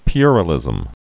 (pyrə-lĭzəm, pwĕrə-, pyər-ə-)